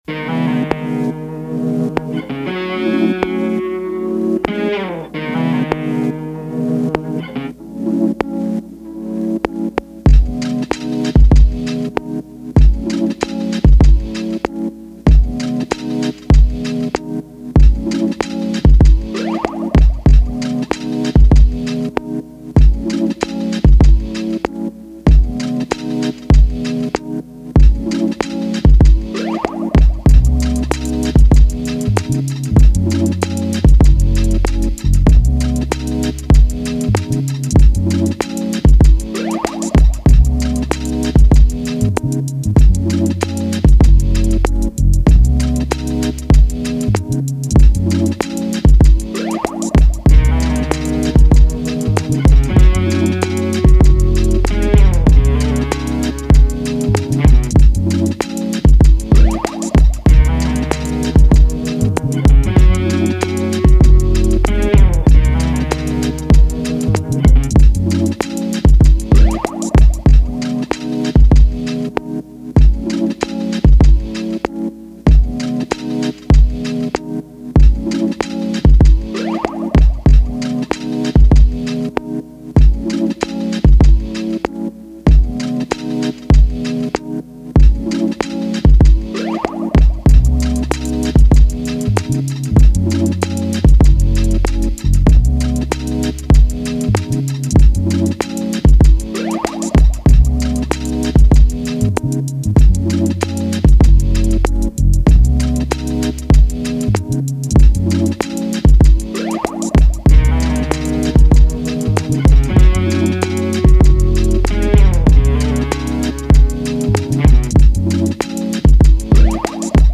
R&B
C# Minor